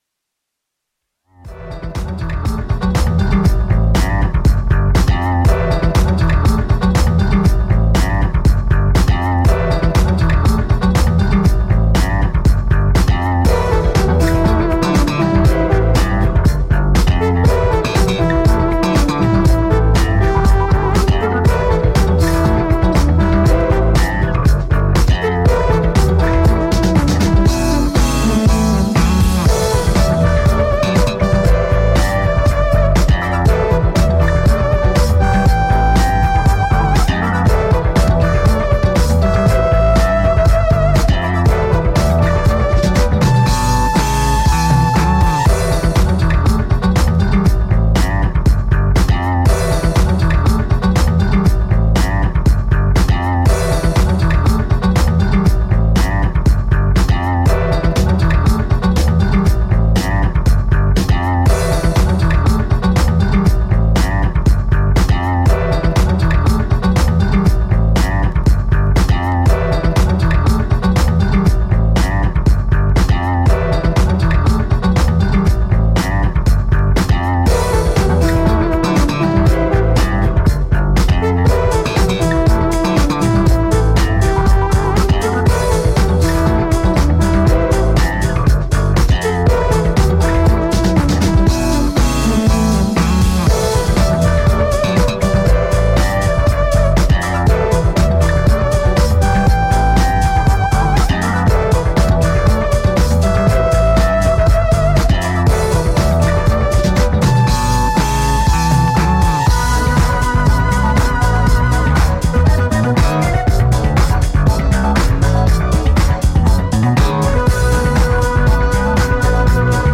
ジャンル(スタイル) NU DISCO / HOUSE / RE-EDIT